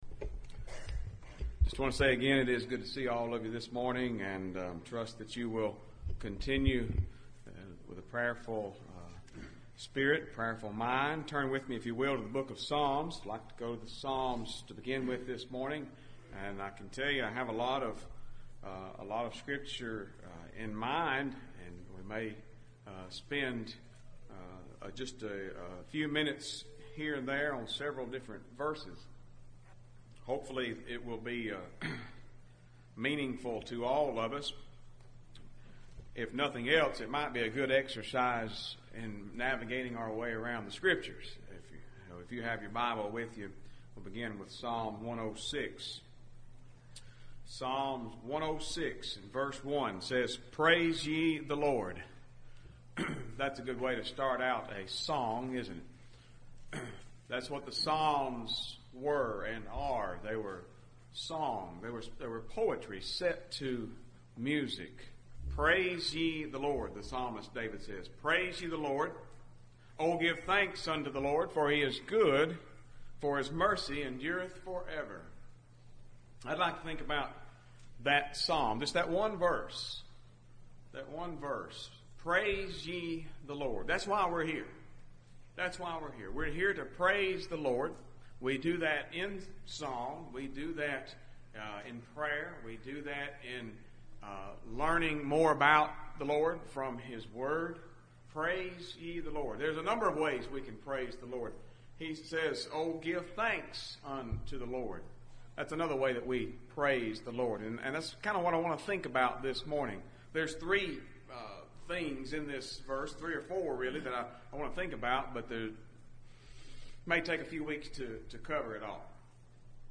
A recent Sunday morning message from the pulpit of Rocky Mount Church.